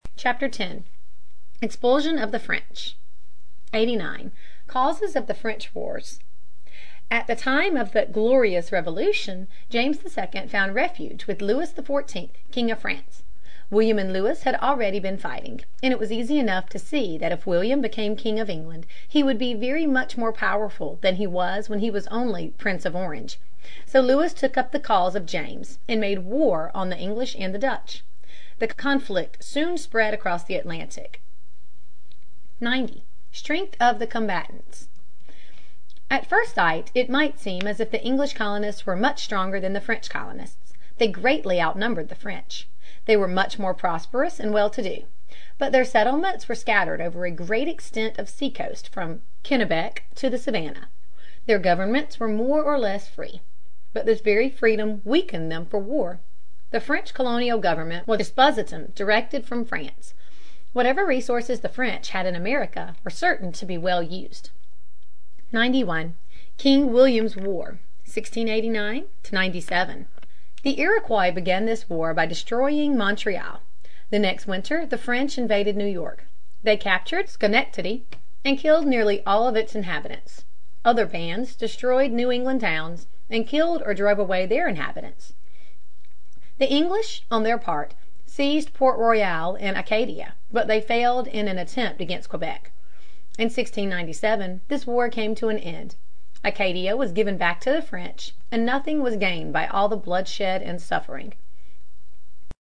在线英语听力室美国学生历史 第29期:驱除法国人(1)的听力文件下载,这套书是一本很好的英语读本，采用双语形式，配合英文朗读，对提升英语水平一定更有帮助。